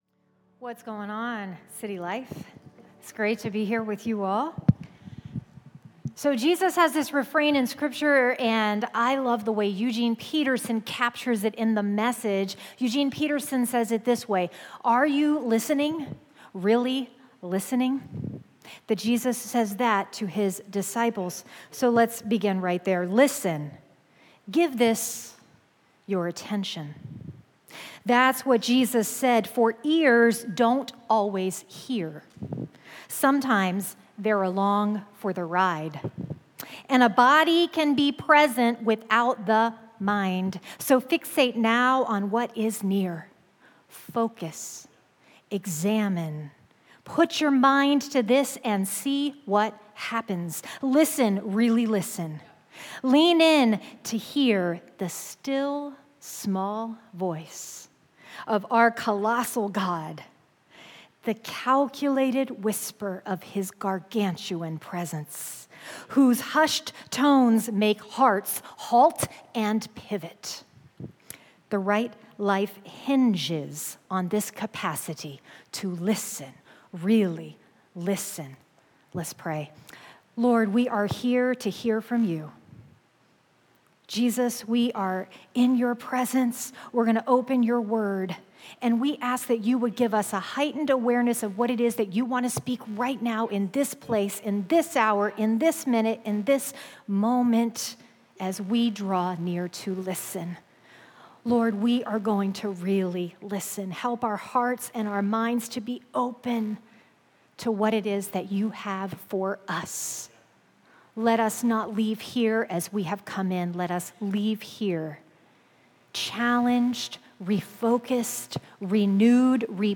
Sermons Caterpillar Soup